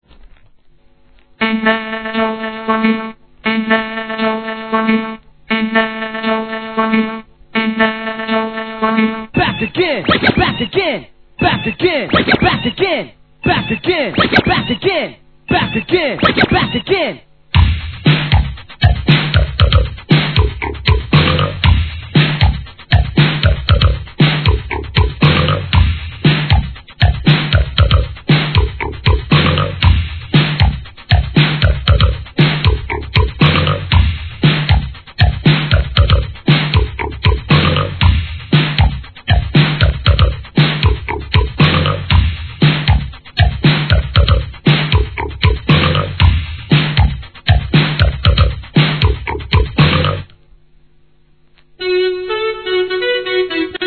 1999年、定番エレクトロ・ブレイクを多数収録のクリエイター向けTOOLです!!